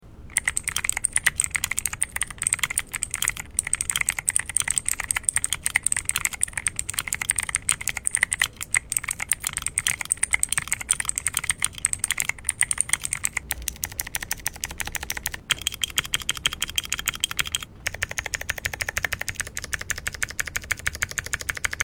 The sound profile largely depends on the switch type, here’s a comparison of typing sounds from different Akko switches, tap the product images below to hear how each switch sounds in action:
Akko Creamy Cyan Switch（Clicky Tactile）
How-Loud-Are-Mechanical-Keyboards-Akko-Creamy-Cyan.mp3